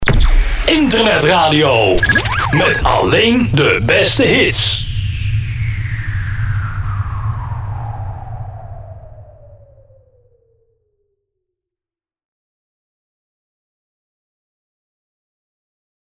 Gesproken jingle
Met Soundeffects